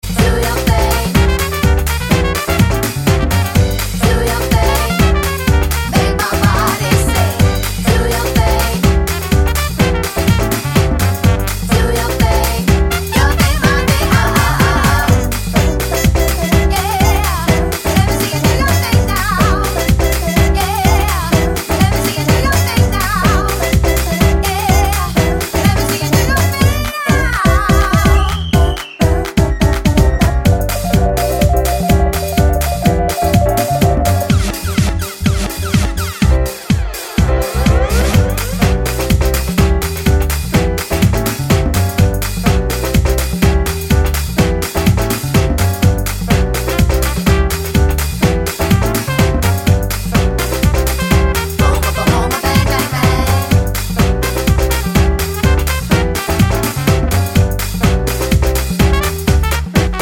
no Backing Vocals Dance 4:15 Buy £1.50